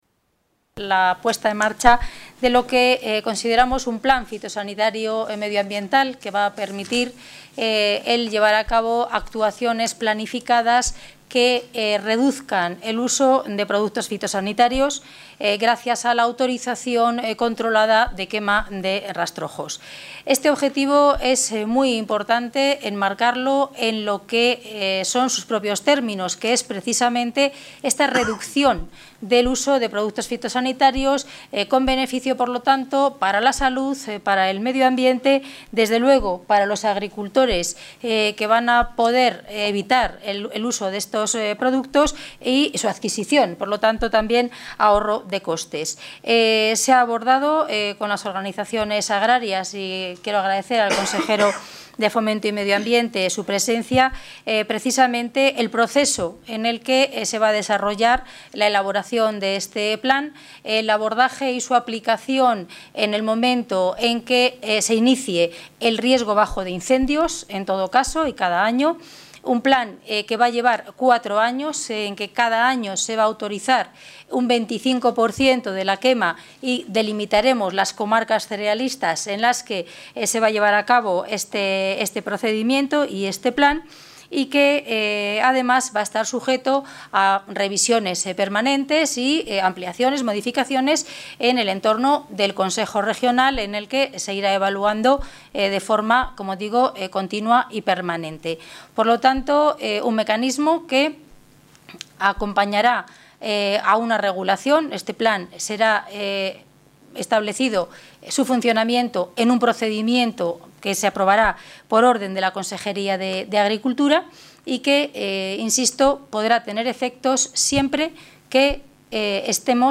Galería Multimedia Audio rueda de prensa tras el Consejo regional agrario Consejo regional agrario Consejo regional agrario Consejo regional agrario Consejo regional agrario